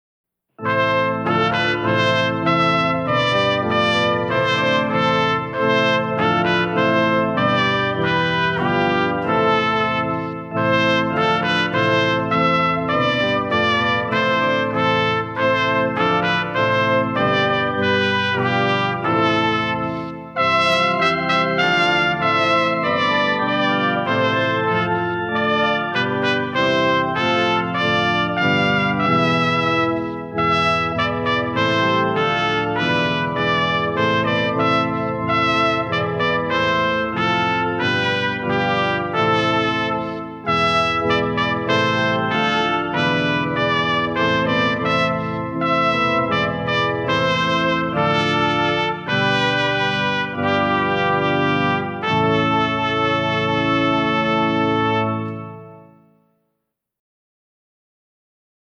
Oratorio contemporaneo